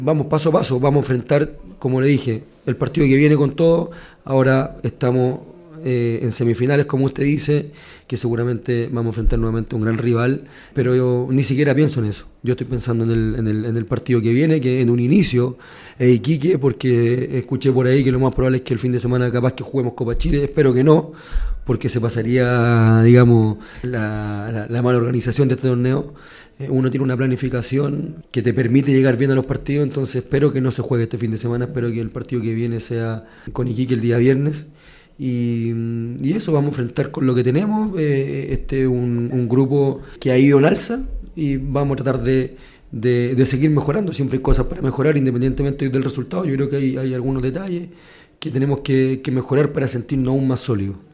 Así lo comentó tras el triunfo de San Marcos de Arica en el Tierra de Campeones.